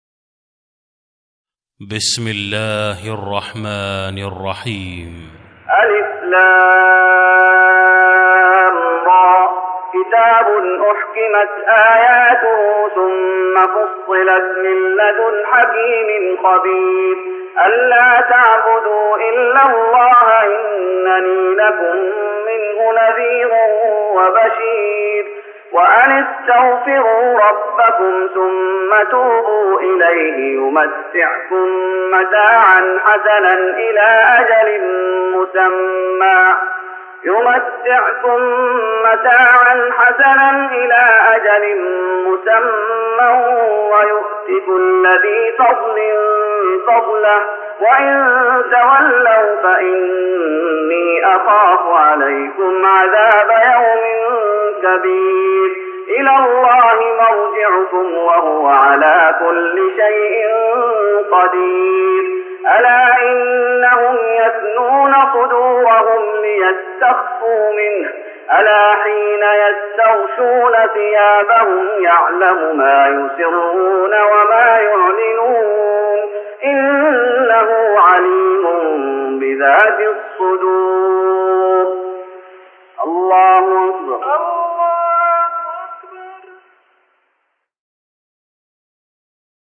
تراويح رمضان 1414هـ من سورة هود (1-5) Taraweeh Ramadan 1414H from Surah Hud > تراويح الشيخ محمد أيوب بالنبوي 1414 🕌 > التراويح - تلاوات الحرمين